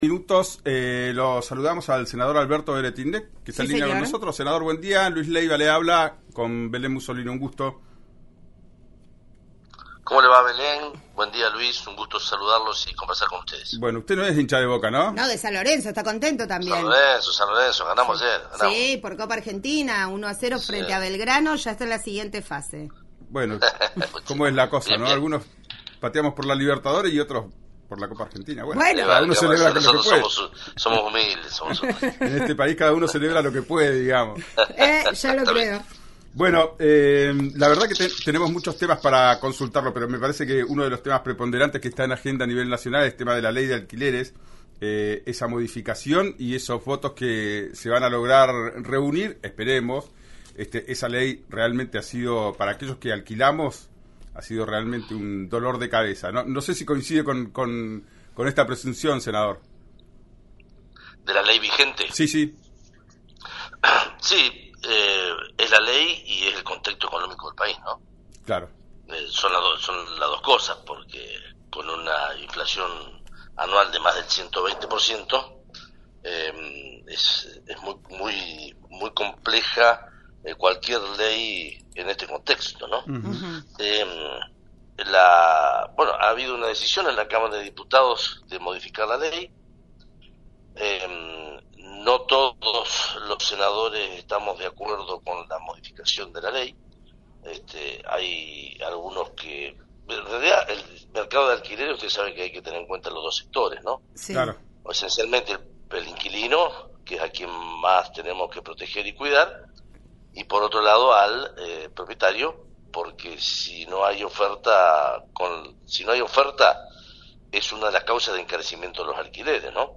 En diálogo con RÍO NEGRO RADIO, el senador rionegrino consideró que 'está bien bajar de tres a dos años el plazo del contrato' pero anticipó que propondrá que el valor del alquiler se incremente dos veces al año.